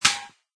plasticmetal2.mp3